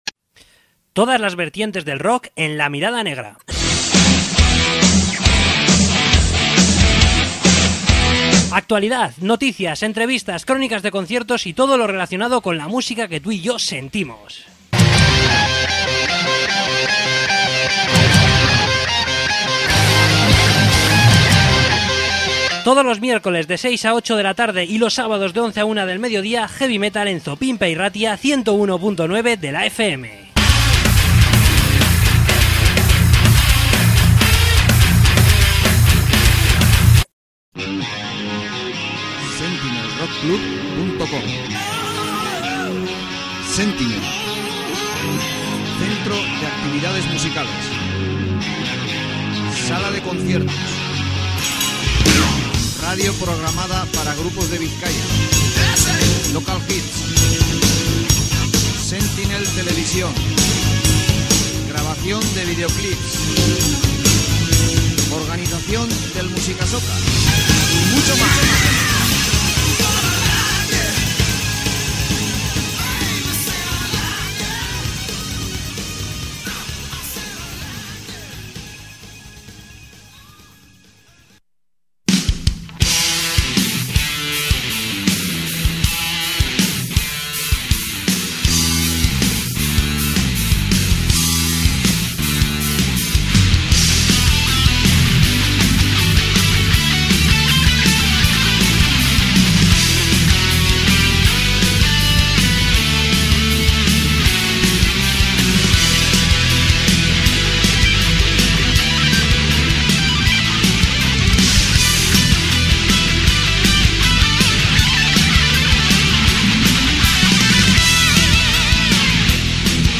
Entrevista con Bullets Of Misery